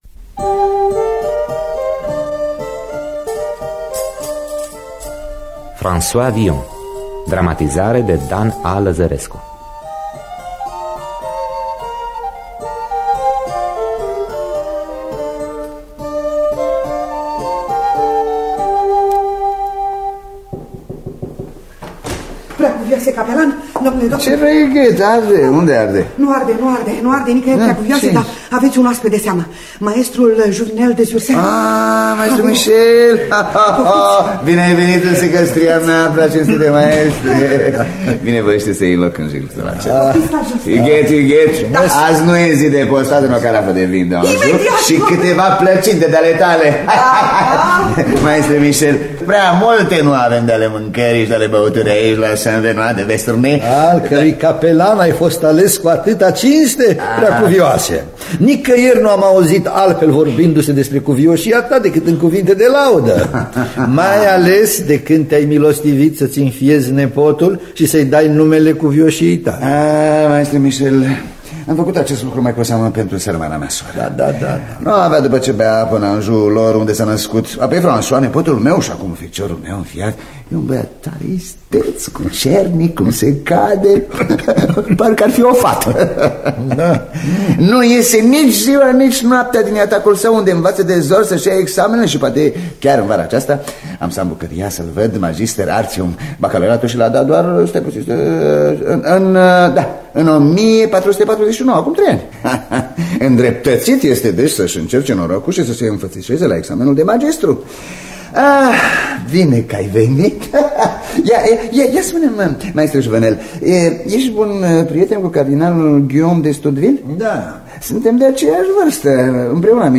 Scenariu radiofonic de Dan Amedeo Lăzărescu.